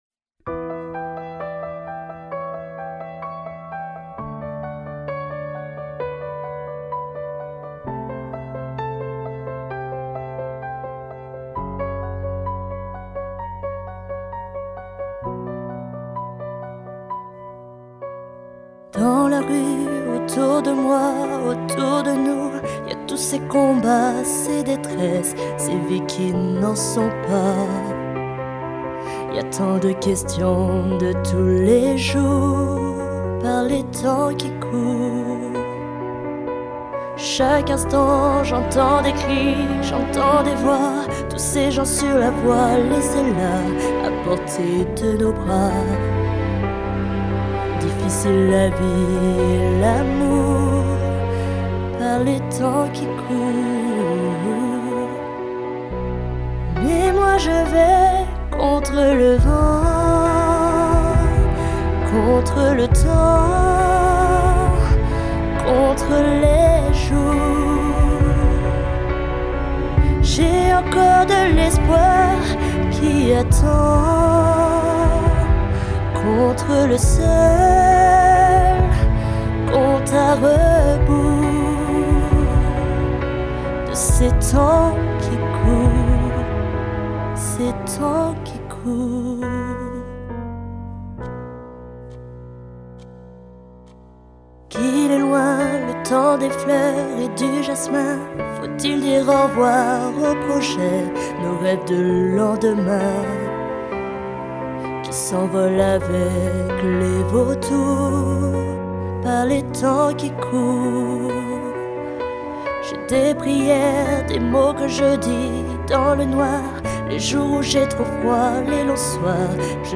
Cover
Chanteuse, variété, pop, soul.
Chanteuse